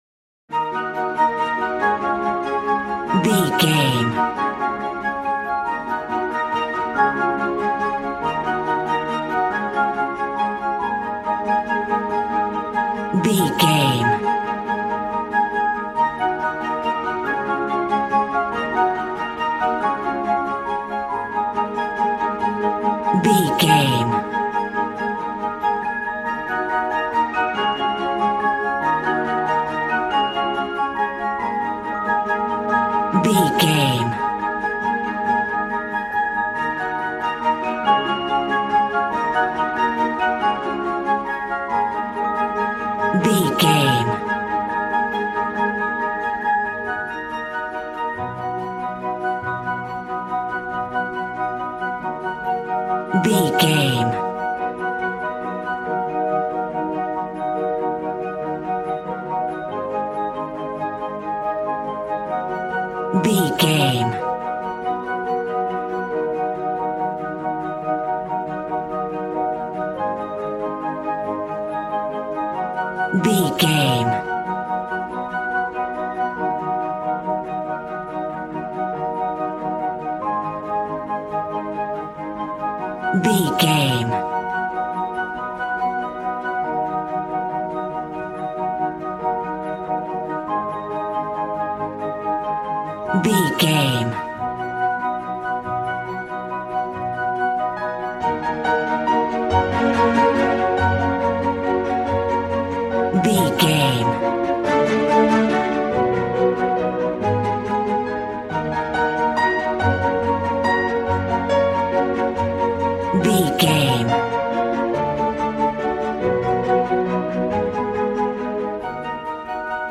Regal and romantic, a classy piece of classical music.
Ionian/Major
G♭
regal
strings
violin